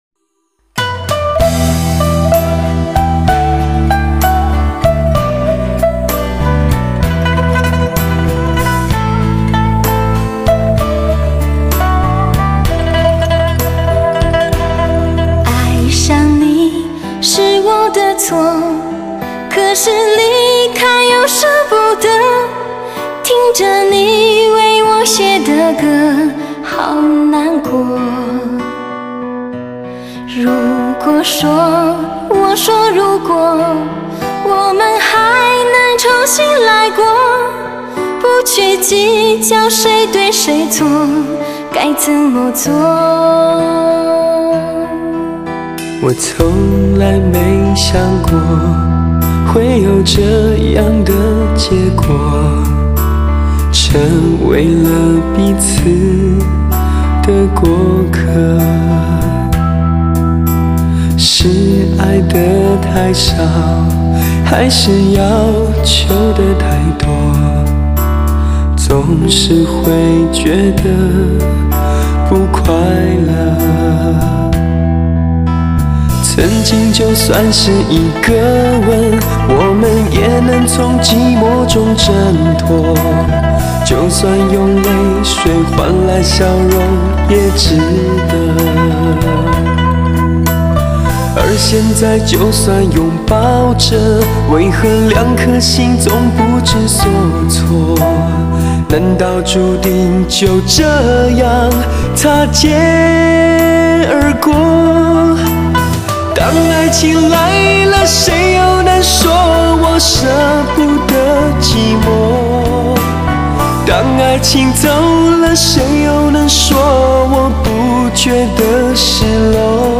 深情代表作 首席疗伤音乐女声魅力
最真实的声音 无可挑剔 最深情的感动